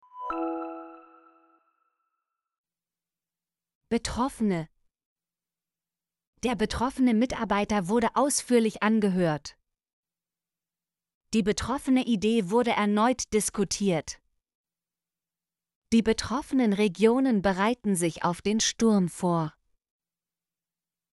betroffene - Example Sentences & Pronunciation, German Frequency List